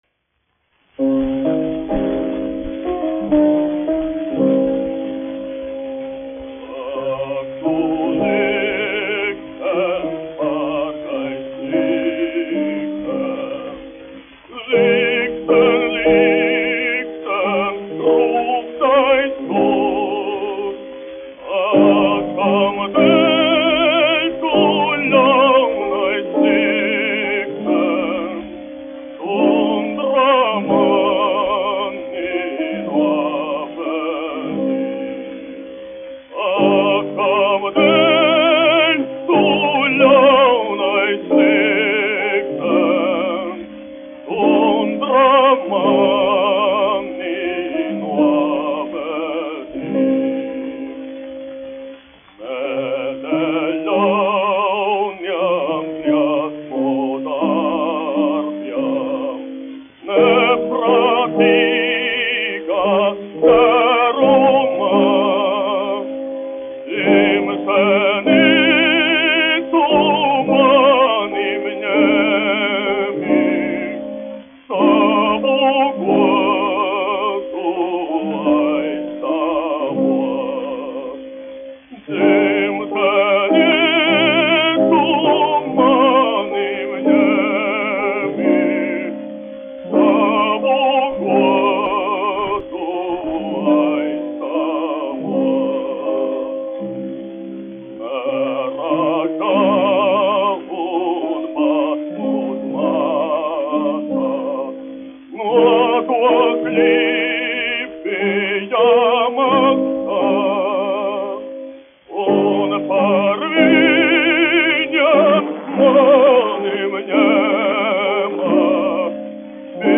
1 skpl. : analogs, 78 apgr/min, mono ; 25 cm
Dziesmas (zema balss) ar klavierēm
Latvijas vēsturiskie šellaka skaņuplašu ieraksti (Kolekcija)